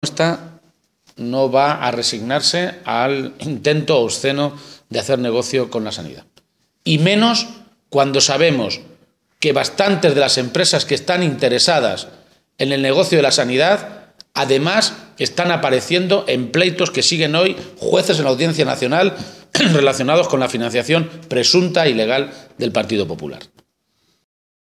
Emiliano García-Page ha realizado estas declaraciones antes de reunirse con la Coordinadora en Defensa del Hospital Público de Almansa, a quienes ha transmitido dos compromisos: “que en el primer año de legislatura de reconstrucción de Castilla-La Mancha vamos a revertir el proceso de negocio que quieren hacer algunos con la sanidad. También pelearemos en vía judicial para que no se produzca el uso abusivo del negocio con la sanidad que quiere hacer Cospedal”.